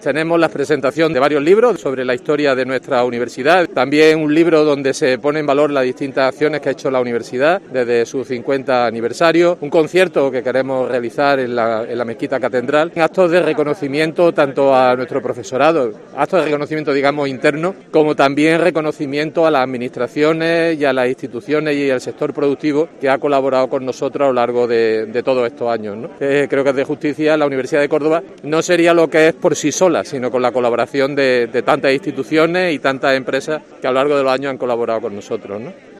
Escucha a José Carlos Gómez, rector de la UCO